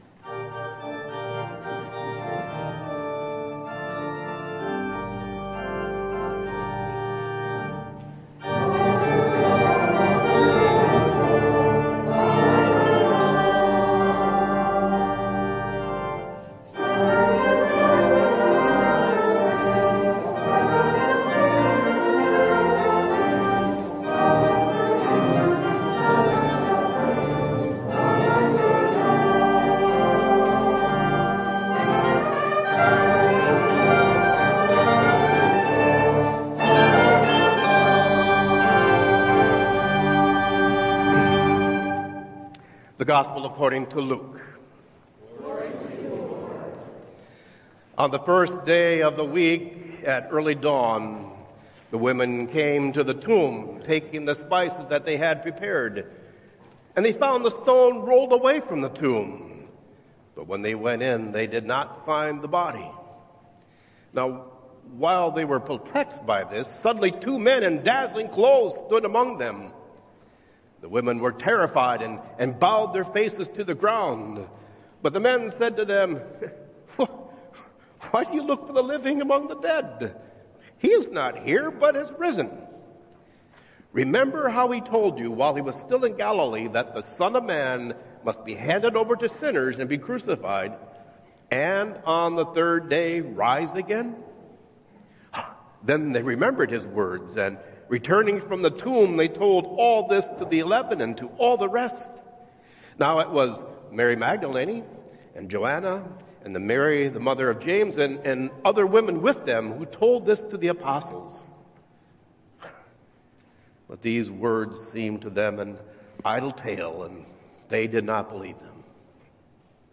Easter Sunday Worship
Sermon Notes